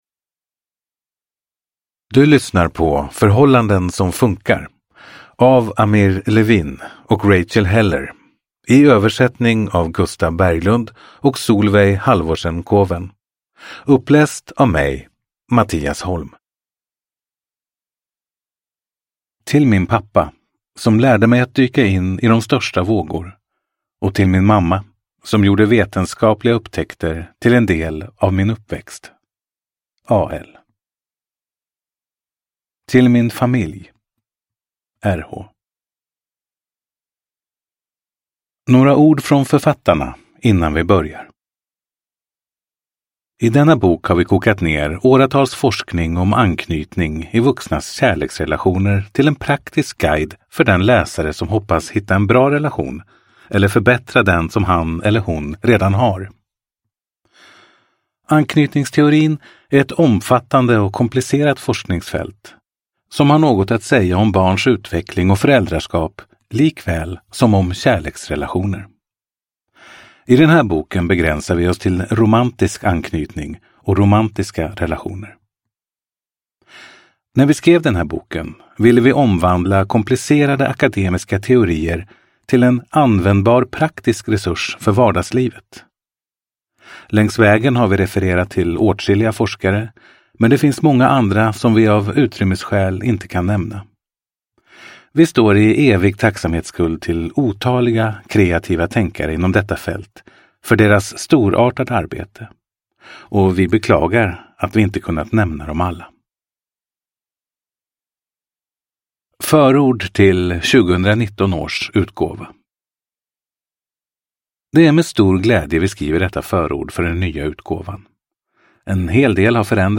Förhållanden som funkar – Ljudbok – Laddas ner